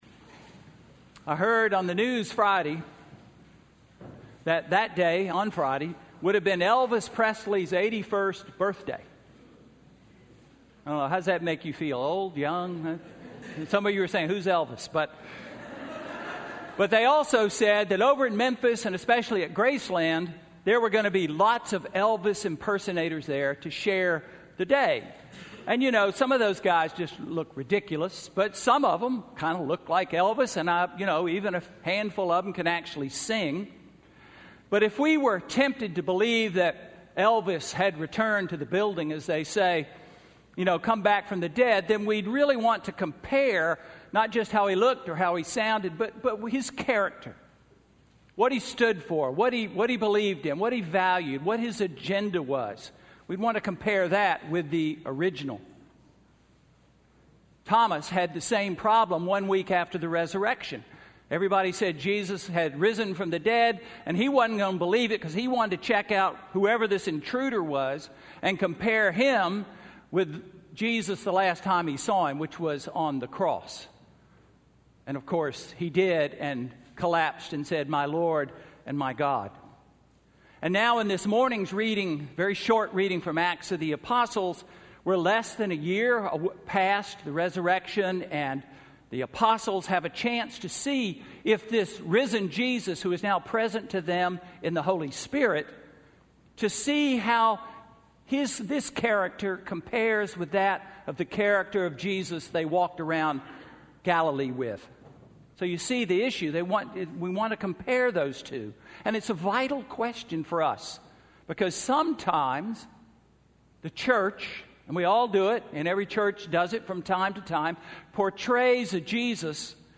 Sermon–January 10, 2016 – All Saints' Episcopal Church